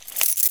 Key Chain Zip 2 Sound
household